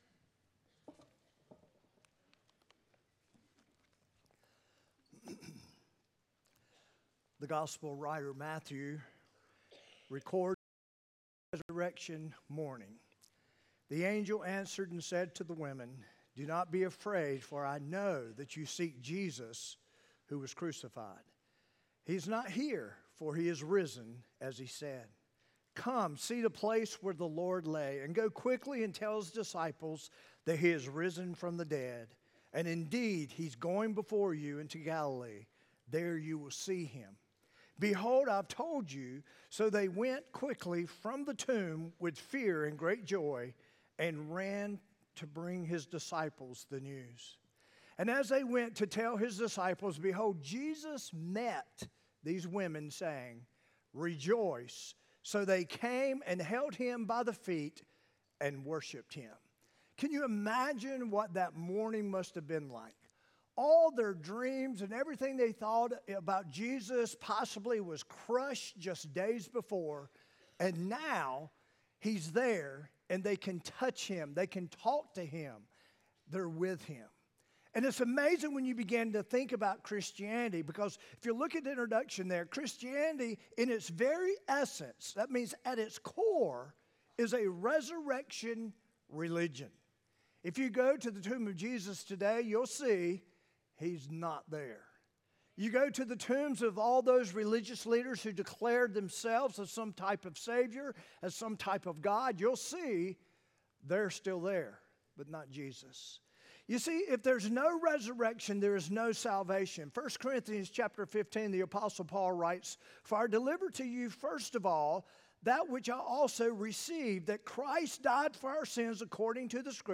4-20-25-sermon-audio.m4a